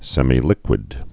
(sĕmē-lĭkwĭd, sĕmī-)